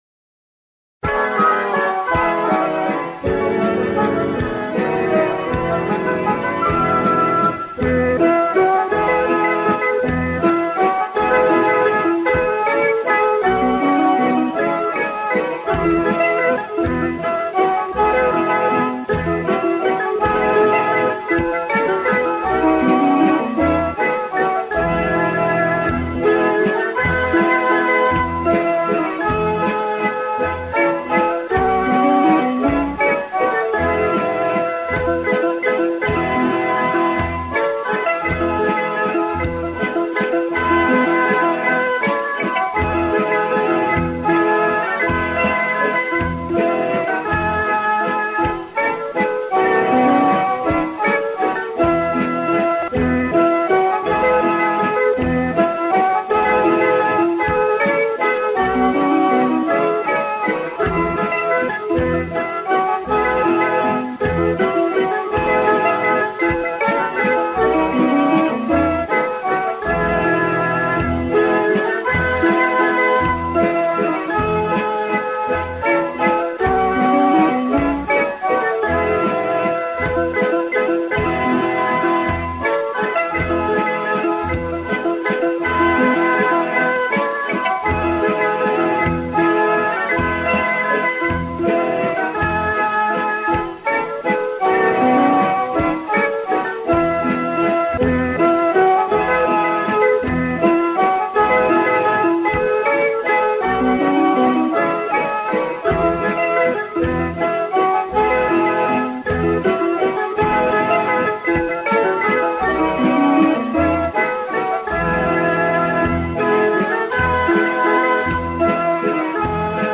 Click to play EnPaavamTheerndhaNaalayae [This accompaniment includes a prelude before the first stanza and an interlude before other stanzas]